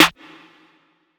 TC2 Snare 3.wav